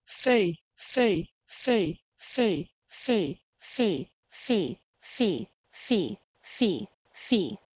E.g. 2. Middle English feh "fee" had a mid, front vowel, but modern English "fee" has a close, front vowel, so the change was something like this (listen):
fEE-to-fee.wav